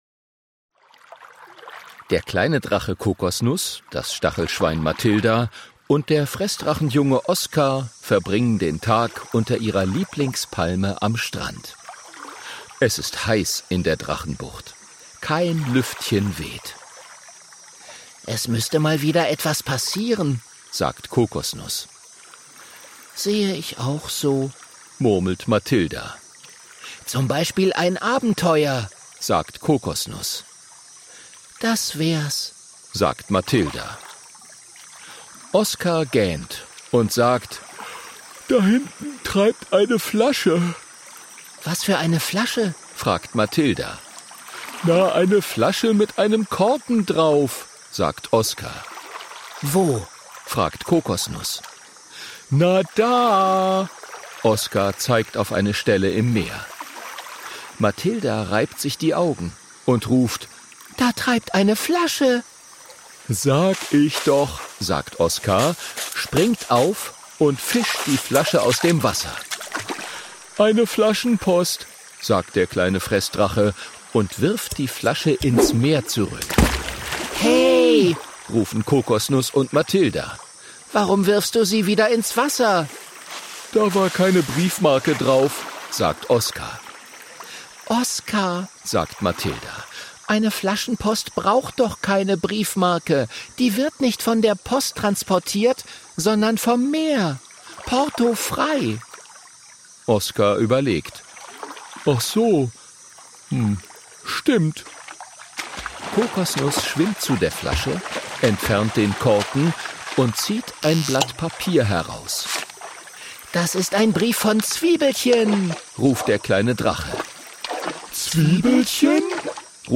ungekürzte Ausgabe, inszenierte Lesung mit Musik